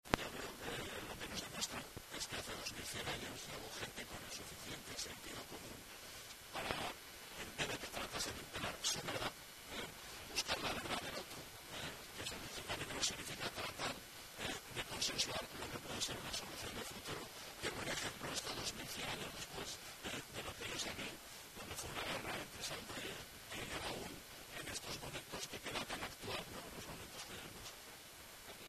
El Consejero de Urbanismo, Infraestructuras, Equipamientos y Vivienda de Zaragoza, Carlos Pérez Anadón, animó a los zaragozanos a disfrutar de la nueva escultura y destacó la capacidad de escuchar la verdad del otro que se encierra en este  conflicto históricoque, por otra parte, tiene muchas dosis de actualidad.